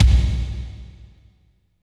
36.08 KICK.wav